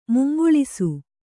♪ mungoḷisu